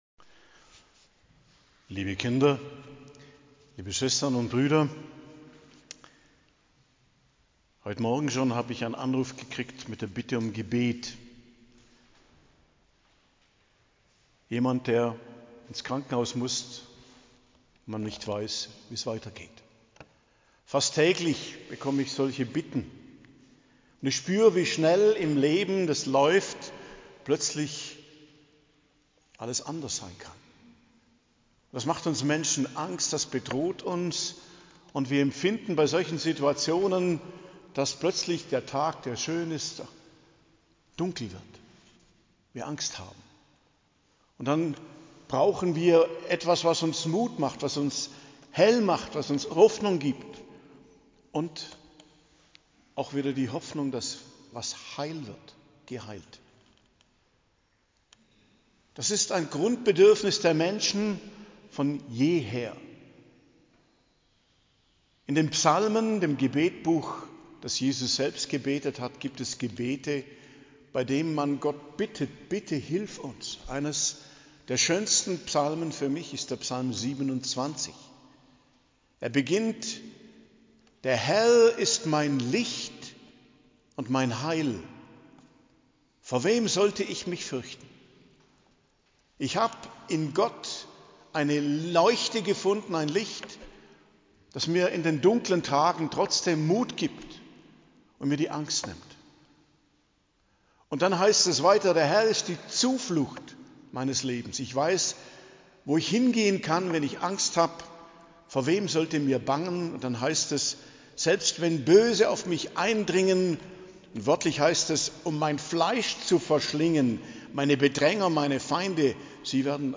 Predigt am Hochfest des Leibes und Blutes Christi, Fronleichnam,19.06.2025